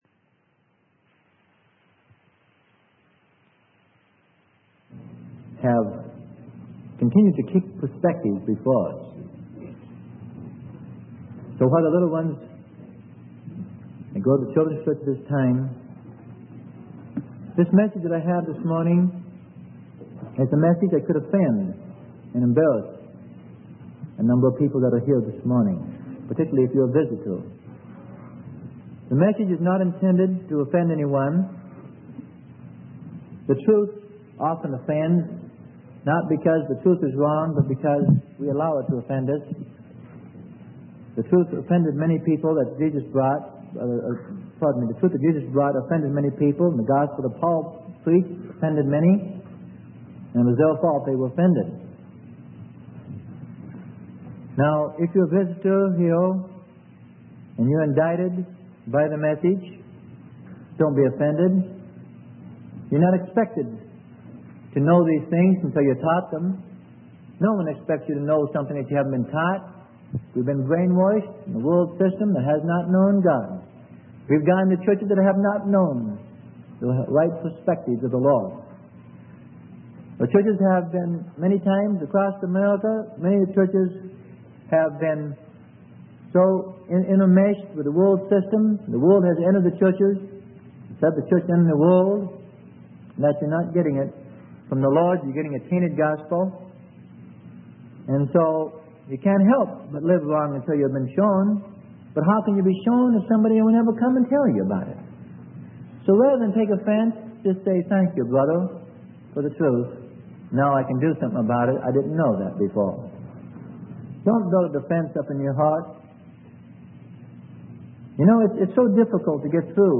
Sermon: Put Off Your Ornaments - Exodus 33 - Freely Given Online Library